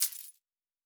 pgs/Assets/Audio/Fantasy Interface Sounds/Coins 11.wav at master
Coins 11.wav